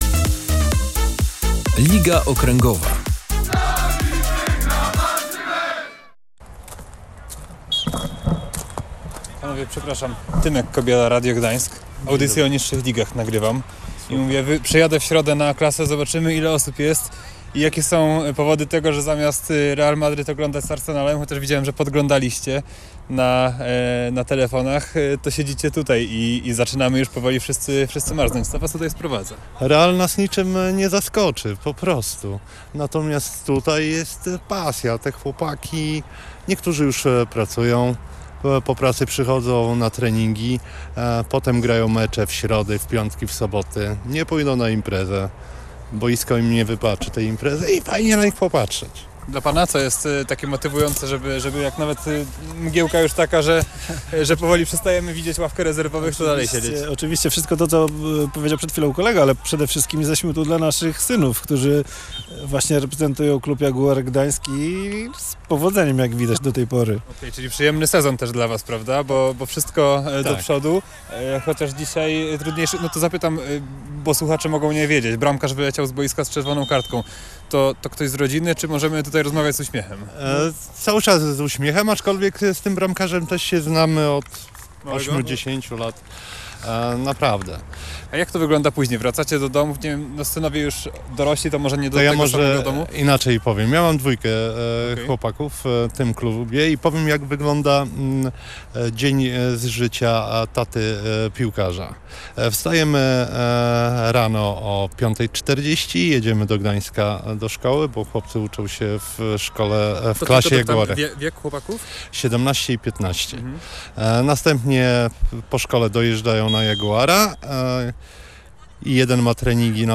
O obu spotkaniach dyskutuje się na jedynej trybunie przy jednym z boisk na gdańskich Kokoszkach, ale dużo większe emocje wzbudzają akcje z meczu A-klasy.